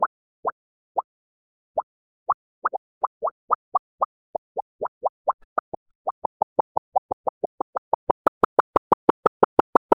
bouncing-around--n3k7z3u7.wav